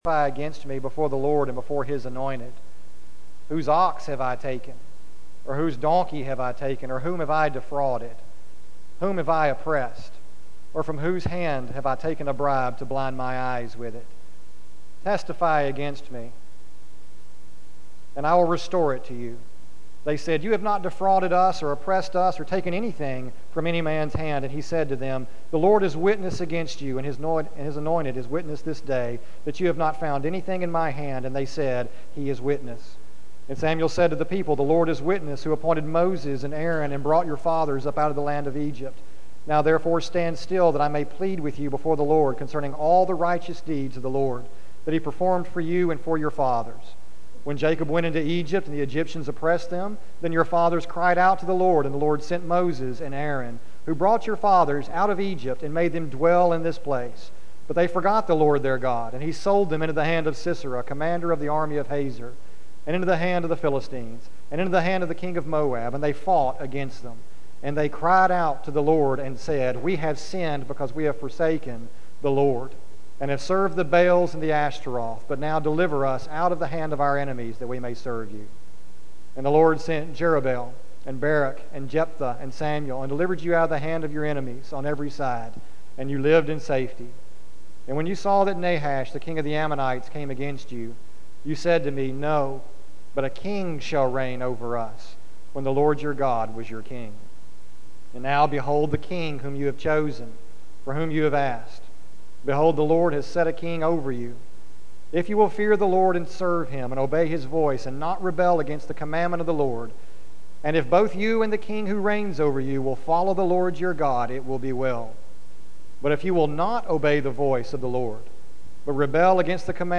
sermon081416.mp3